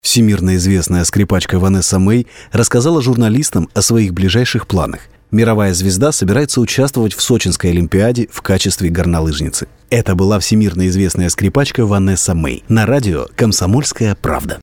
Разноплановый диктор.
Rode NT1000, Long Stereo Channel, TC Electronic Konnekt6